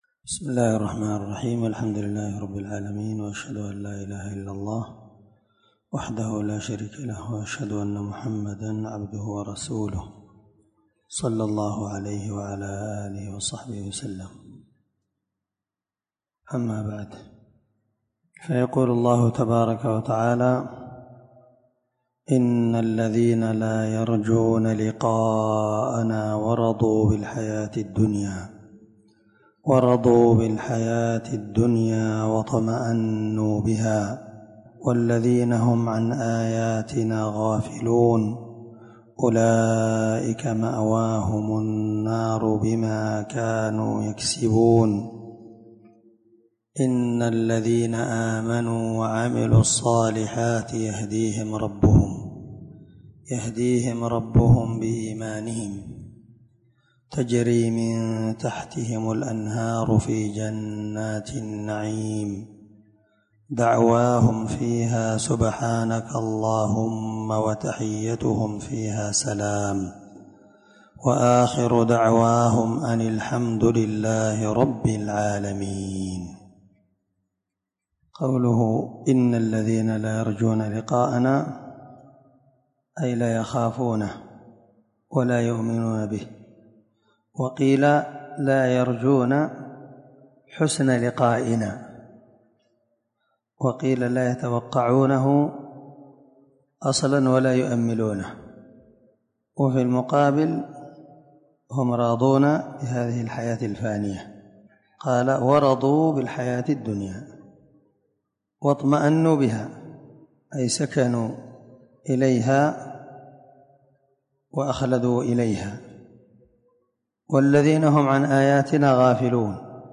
588الدرس 4 تفسير آية ( 7 – 10) من سورة يونس من تفسير القران الكريم مع قراءة لتفسير السعدي
دار الحديث- المَحاوِلة- الصبيحة.